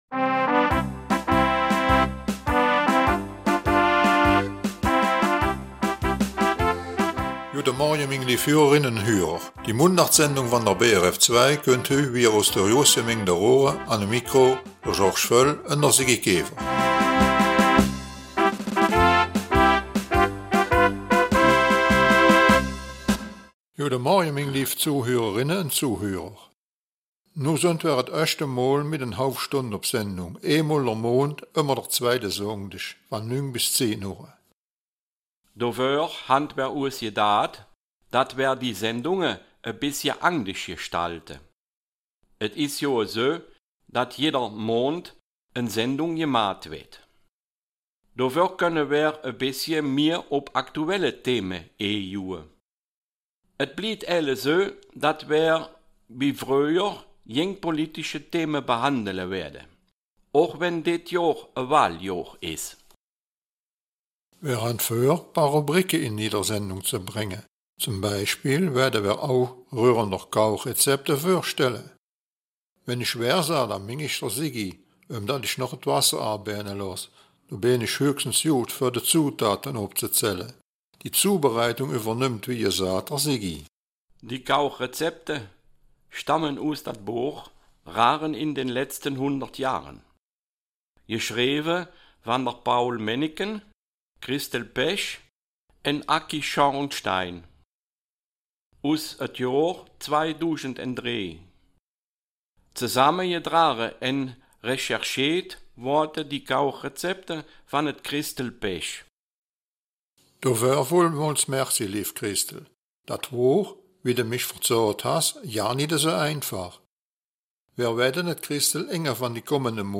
Raerener Mundart